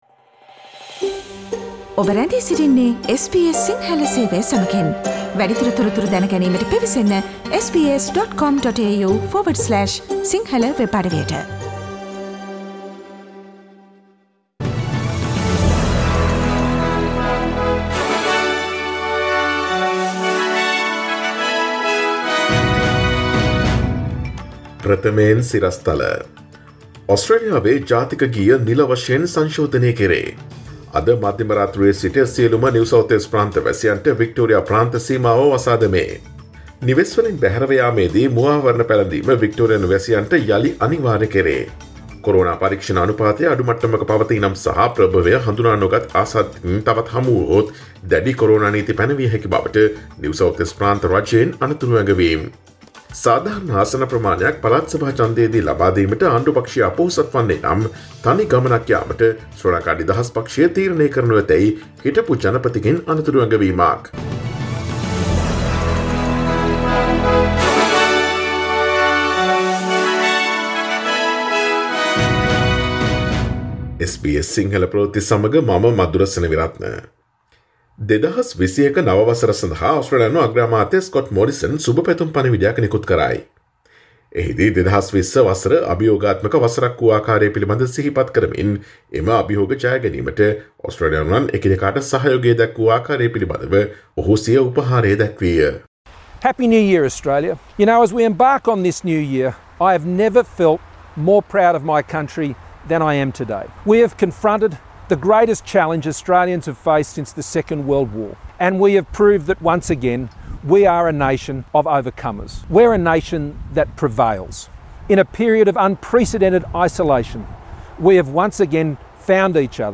Today’s news bulletin of SBS Sinhala radio – Friday 01 January 2021